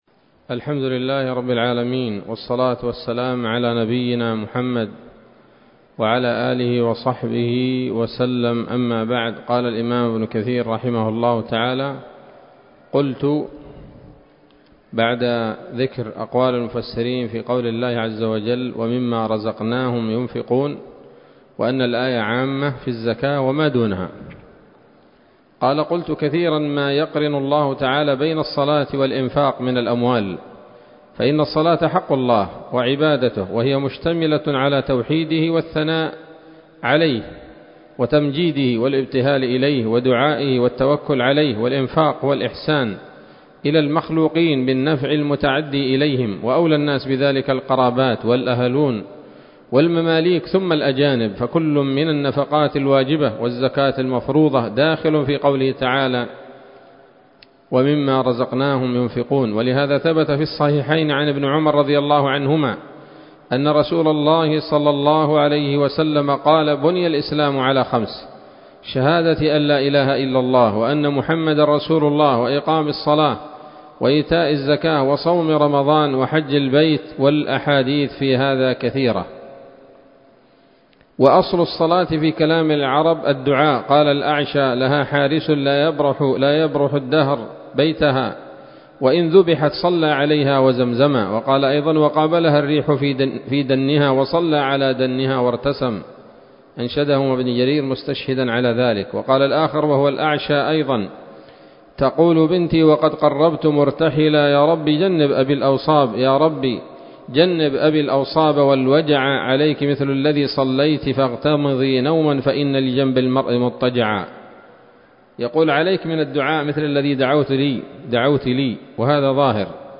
الدرس الحادي عشر من سورة البقرة من تفسير ابن كثير رحمه الله تعالى